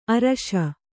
♪ araśa